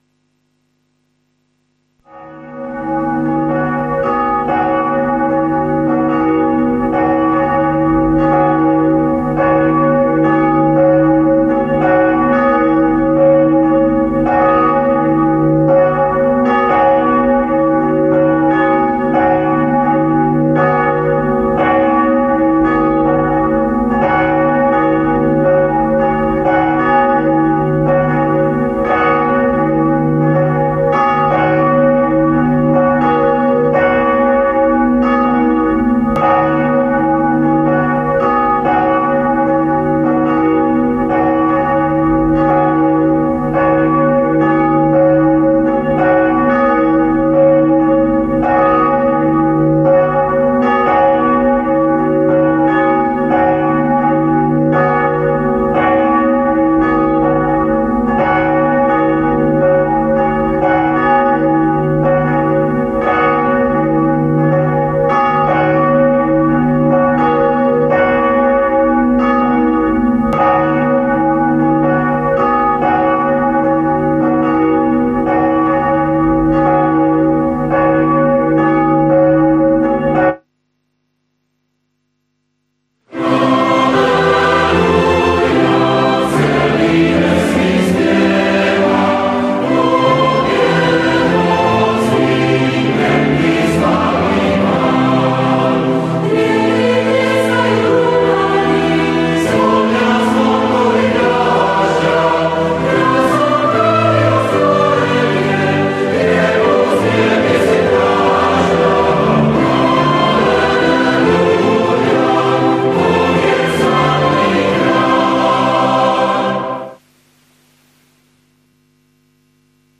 Stránka zboru Cirkvi bratskej v Bratislave - Cukrová 4
- Mal 3:1-5, 19-20a; 1Kor 3:10-15; 2 Pt 3:1-14 Podrobnosti Kázeň Prehliadač nepodporuje prehrávač.